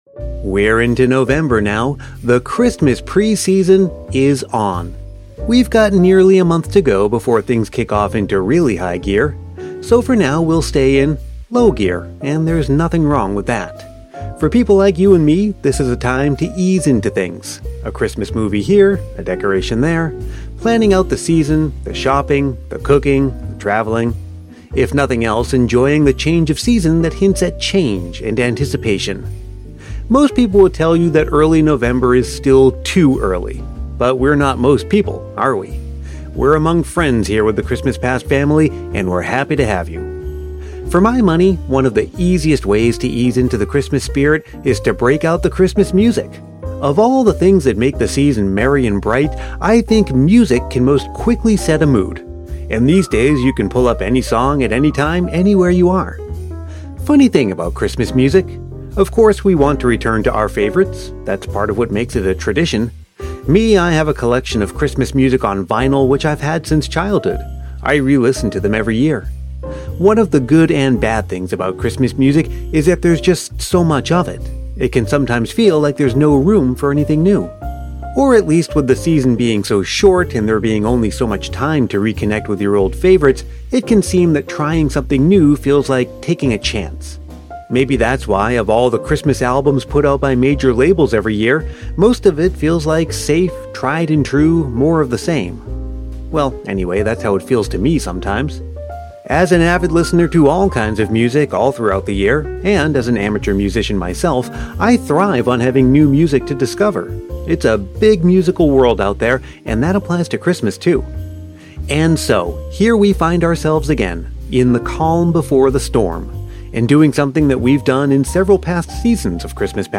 In this episode, we’ve got some new-to-you Christmas songs from independent artists you may not have otherwise discovered on your favorite station or streaming service.